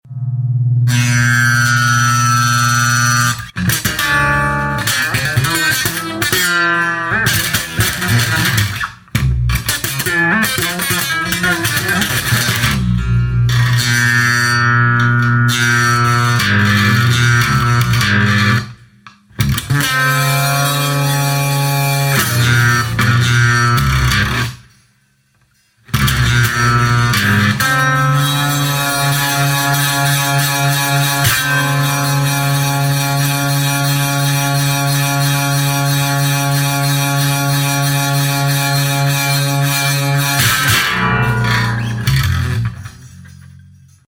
Akustische Gitarren